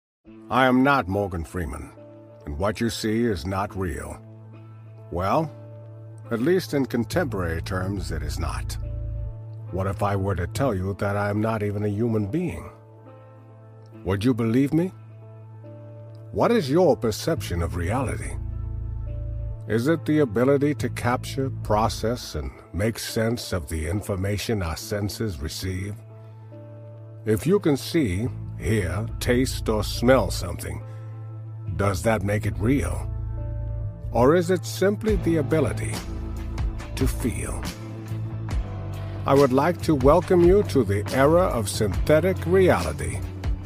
The entire video and audio here is ai generated showing how difficult it is becoming to distinguish between real and artificial content.